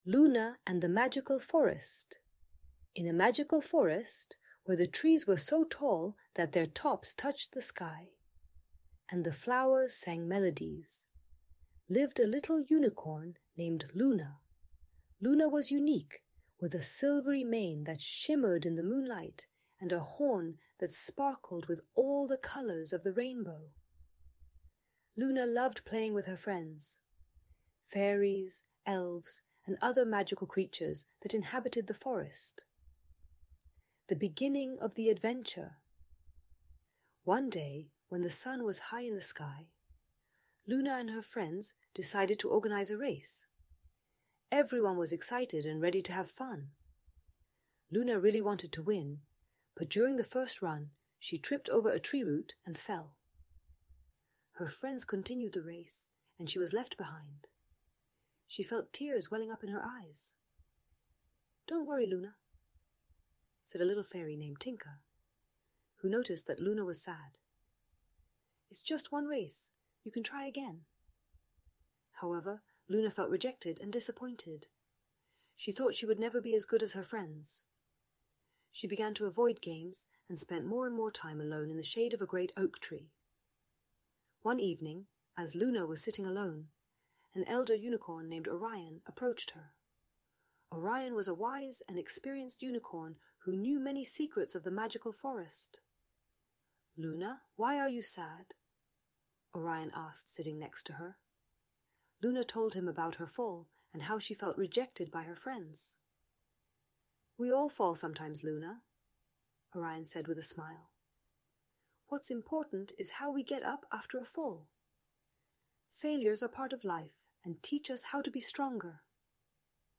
Magical Forest Story - Lęk wycofanie | Audiobajka